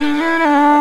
TS - CHANT (1).wav